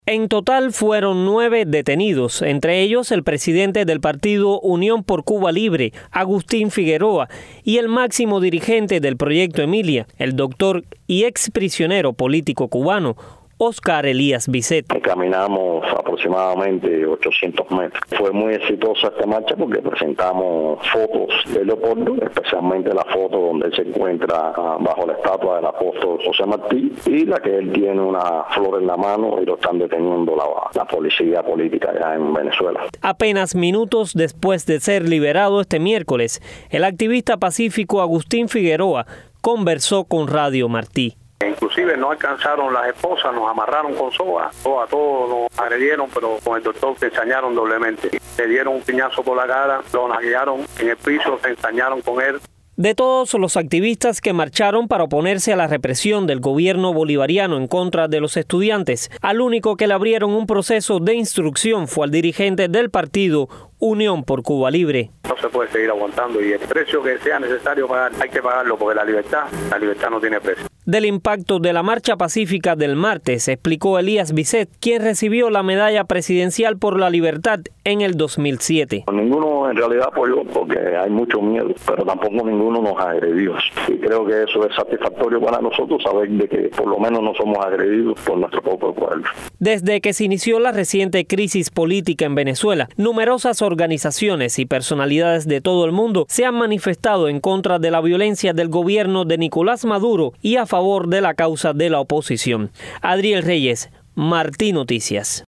Declaraciones de Oscar Elías Biscet a Radio Martí